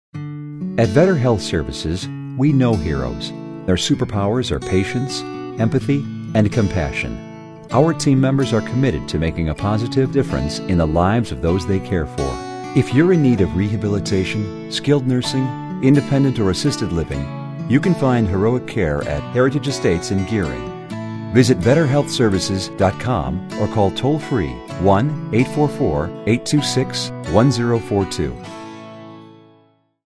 VSL Heroes Radio Spot